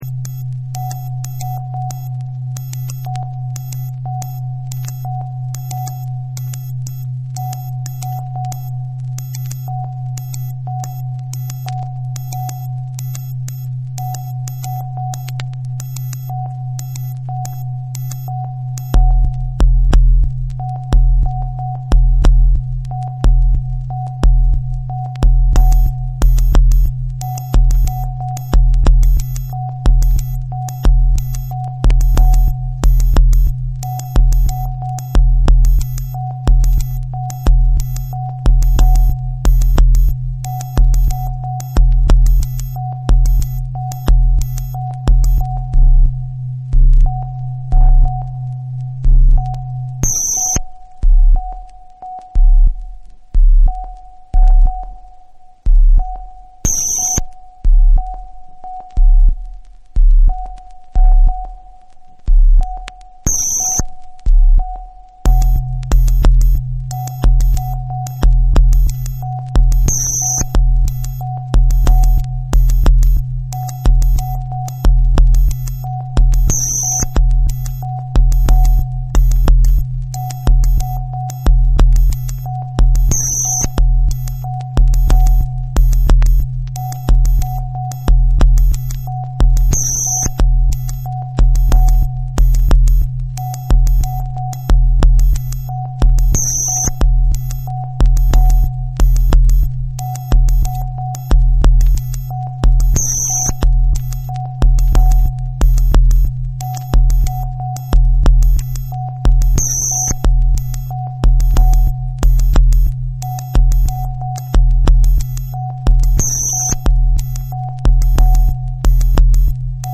NEW AGE & OTHERS / CHILL OUT